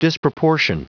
Prononciation du mot disproportion en anglais (fichier audio)
Prononciation du mot : disproportion